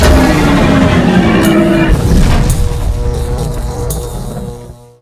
combat / enemy / droid / bigdie3.ogg
bigdie3.ogg